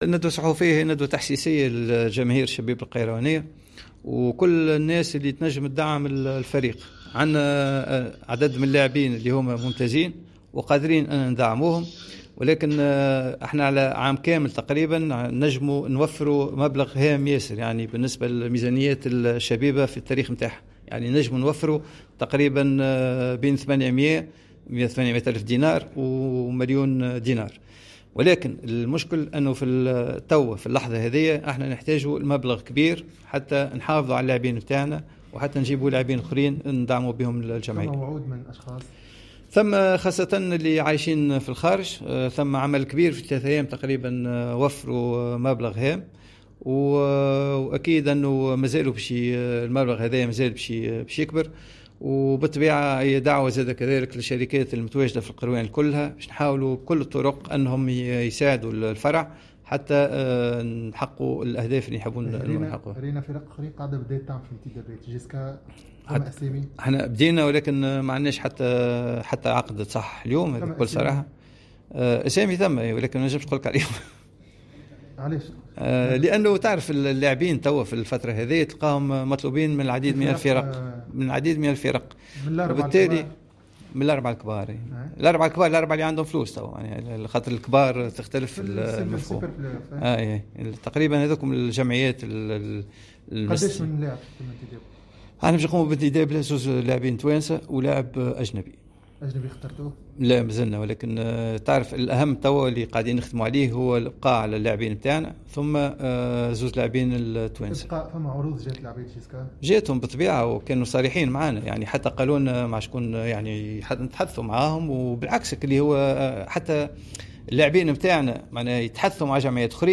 عقد فرع كرة السلة بشبيبة القيروان اليوم الاثنين 24 اوت 2020 ندوة صحفية لمطالبة الاحباء بالدعم المادي من اجل الحفاظ على ركائز الفريق و التمكن من تحقيق الاهداف.